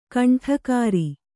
♪ kaṇṭhakāri